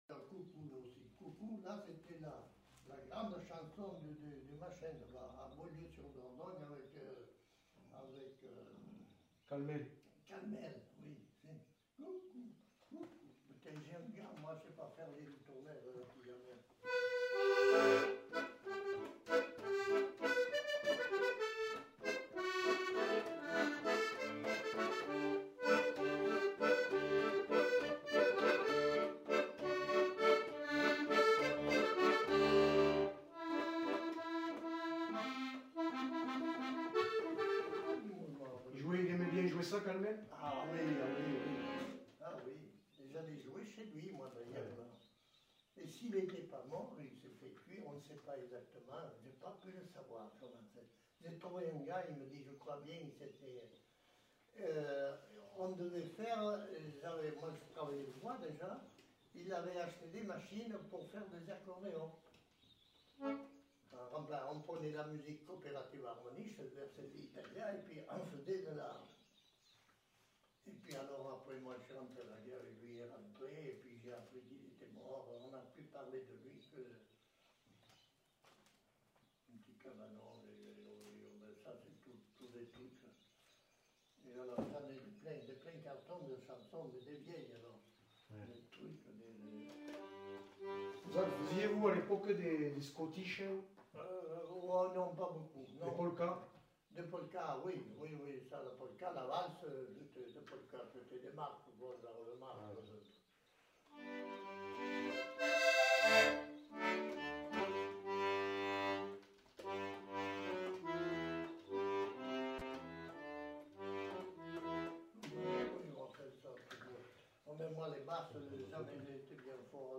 Aire culturelle : Quercy
Lieu : Chasteaux
Genre : morceau instrumental
Instrument de musique : accordéon chromatique
Danse : valse
Notes consultables : Enumération de titres de partitions en fin de séquence.